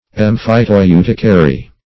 Search Result for " emphyteuticary" : The Collaborative International Dictionary of English v.0.48: Emphyteuticary \Em`phy*teu"ti*ca*ry\, n. [L. emphyteuticarius, a.] One who holds lands by emphyteusis.